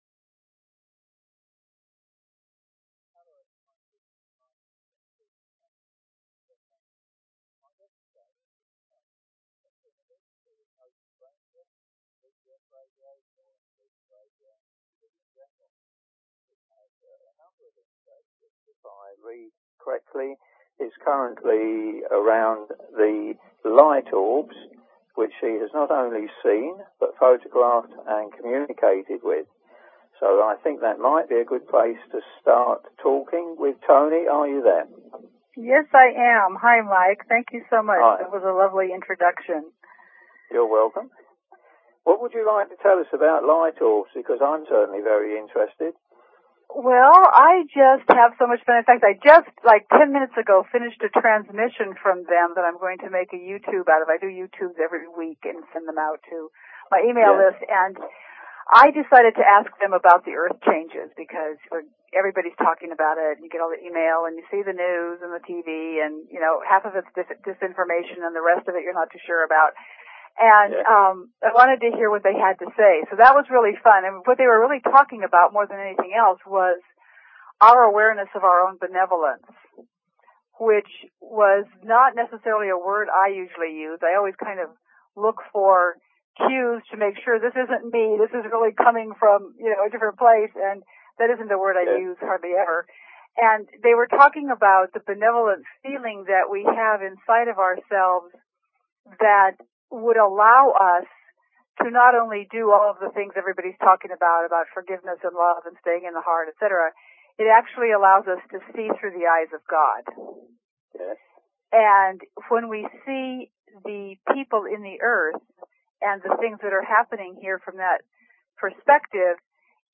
Talk Show Episode, Audio Podcast, Connecting_The_Light and Courtesy of BBS Radio on , show guests , about , categorized as
Unfortunately a break in transmission was experienced about 15 minutes into the program, but it was able to proceed again about another 15 minutes later.